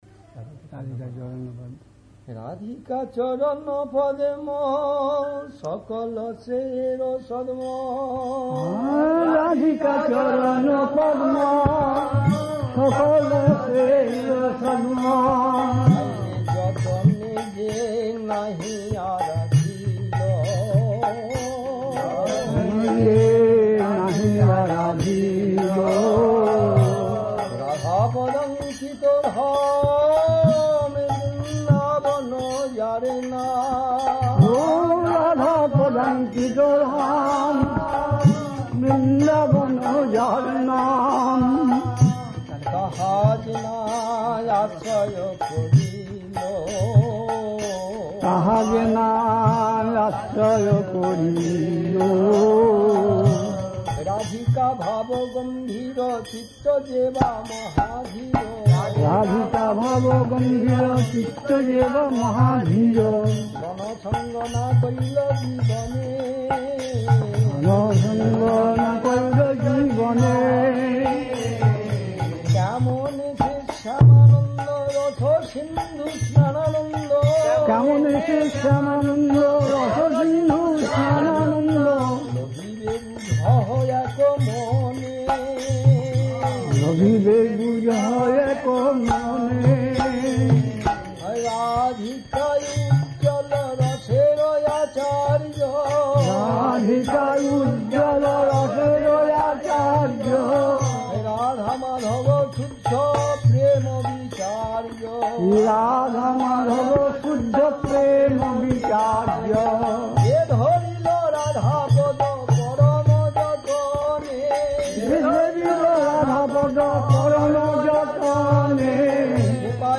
We apologise for the audio quality. It's being posted as vintage classic recordings.
Kirttan